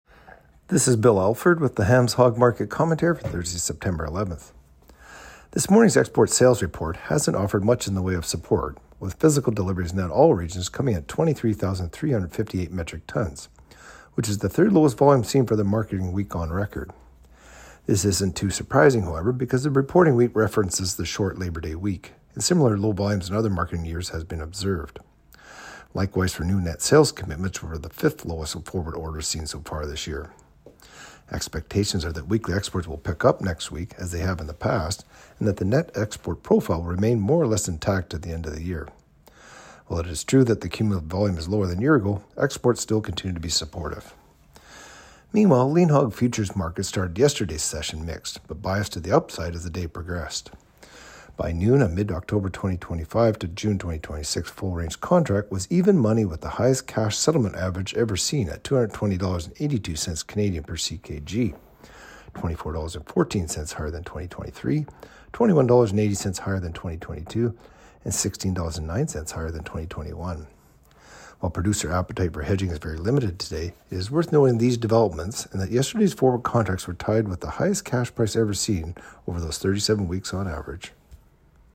Hog-Market-Commentary-Sep.-11-25.mp3